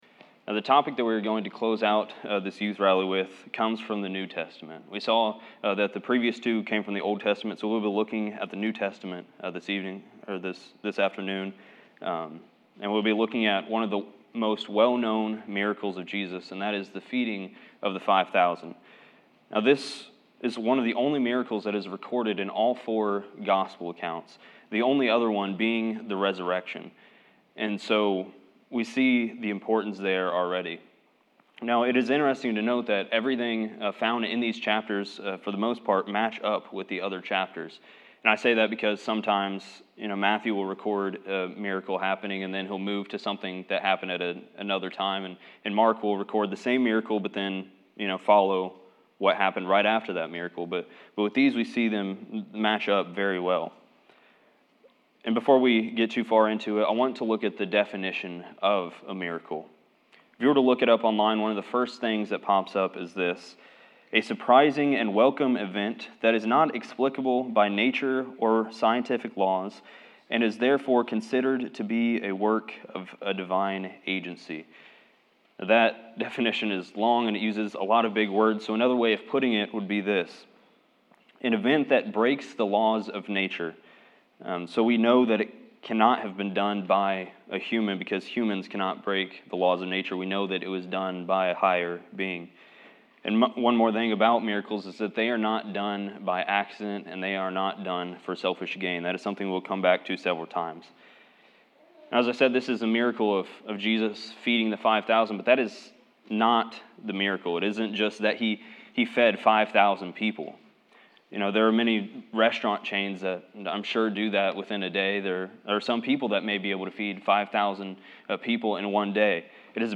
Youth Rally
Service Type: Seminar